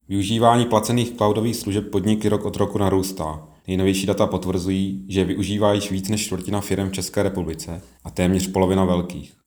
Vyjádření Marka Rojíčka, předsedy ČSÚ, soubor ve formátu MP3, 407.75 kB